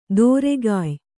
♪ dōregāy